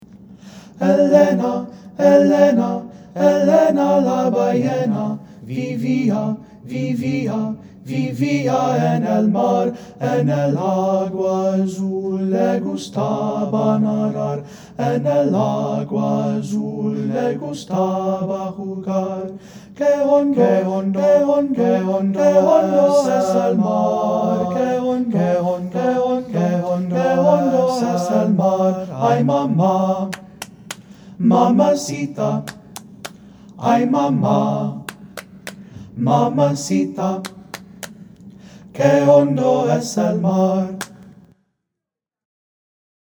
Ensemble: Tenor-Bass Chorus
Key: G major
Tempo: q = 120, q = 110, q. = 88